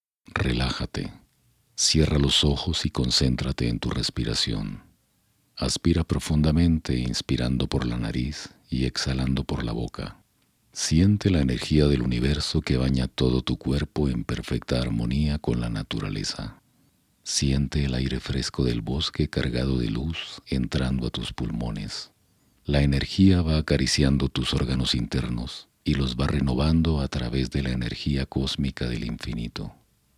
locutor profesional, documentales, actor de voz, comerciales e institucionales
Sprechprobe: Sonstiges (Muttersprache):